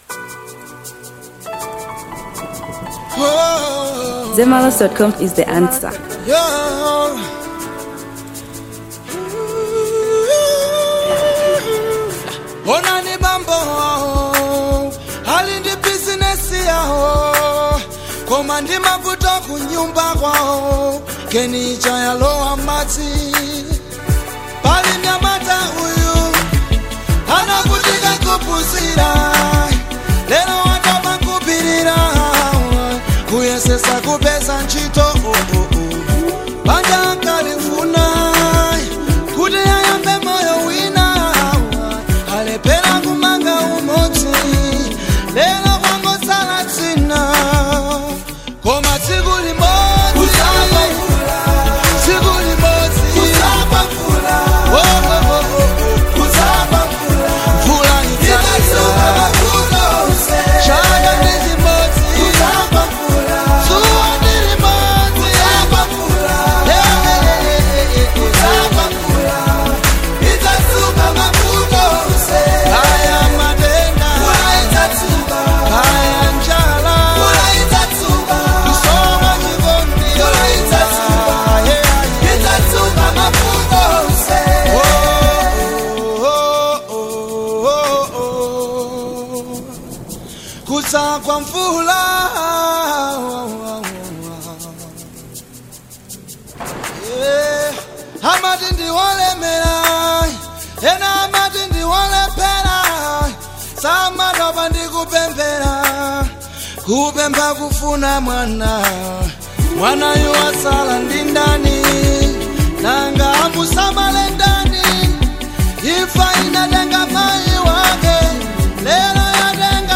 Fusion • 2025-10-27